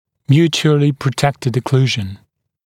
[‘mjuːʧuəlɪ prə’tektɪd ə’kluːʒn][‘мйу:чуэли прэ’тэктид э’клу:жн]взаимно защищенная окклюзия